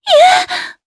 Yuria-Vox_Damage_jp_03.wav